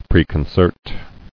[pre·con·cert]